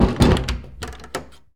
door-close-2.ogg